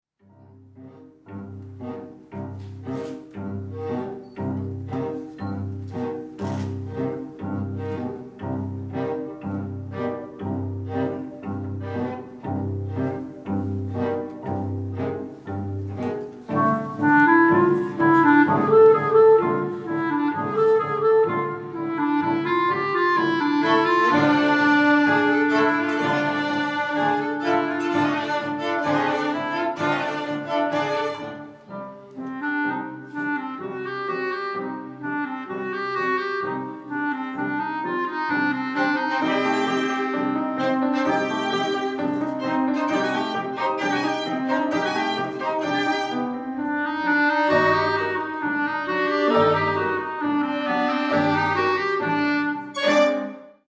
Prokofiev, S.: Ouverture na hebrejské téma pro smyčcové kvarteto, klavír a klarinet